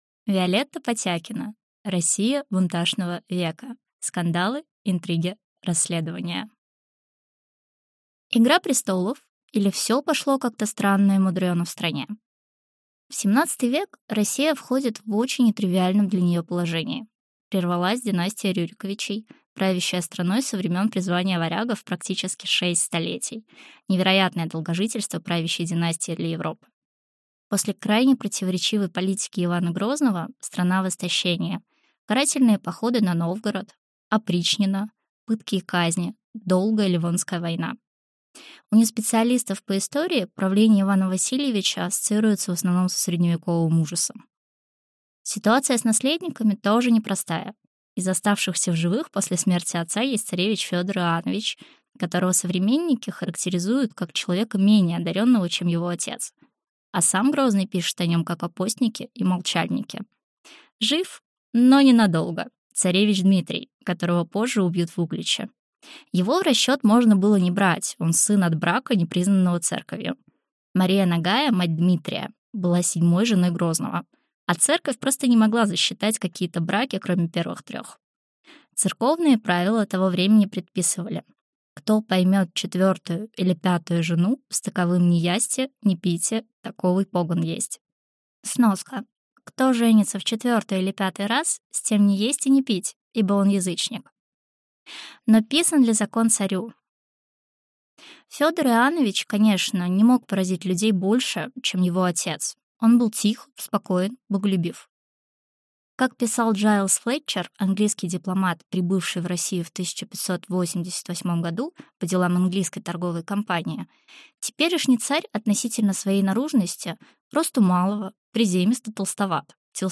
Аудиокнига Россия бунташного века: cкандалы, интриги, расследования | Библиотека аудиокниг